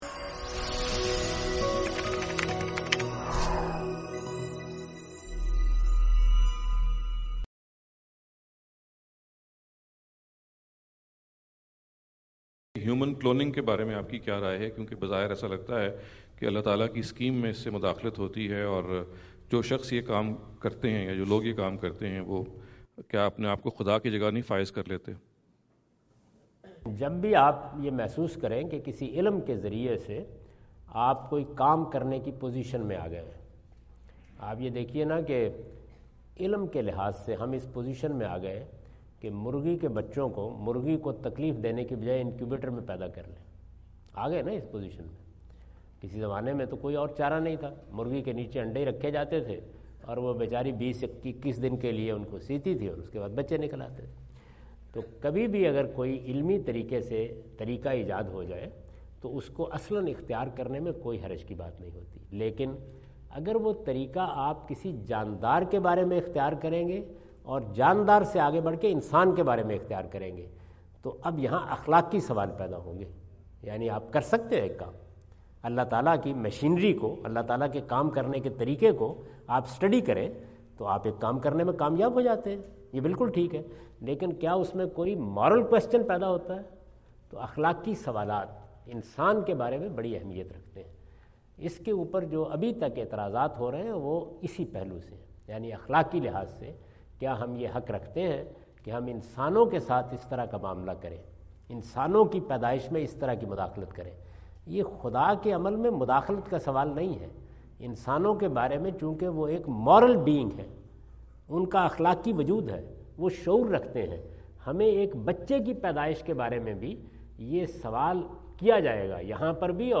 Javed Ahmad Ghamidi answer the question about "Is Human Cloning Permissible in Islam?" asked at Aapna Event Hall, Orlando, Florida on October 14, 2017.